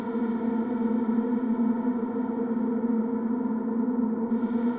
SS_CreepVoxLoopB-02.wav